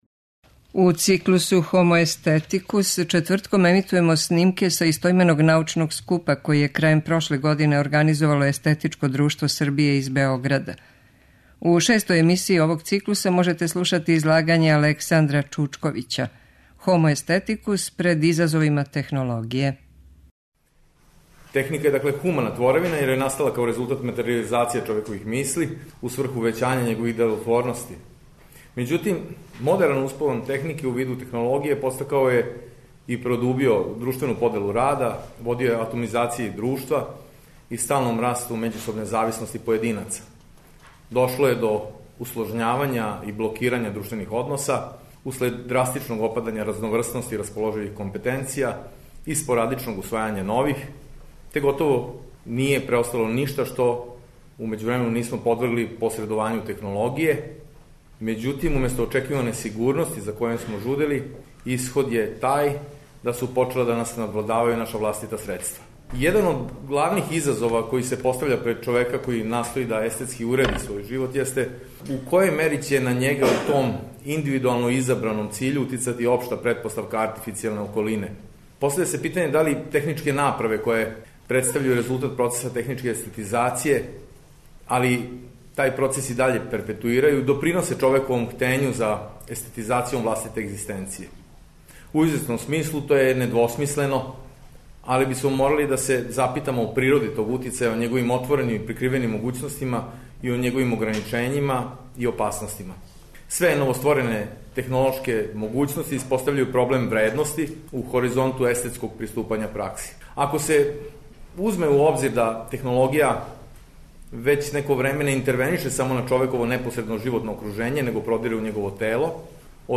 Научни скупoви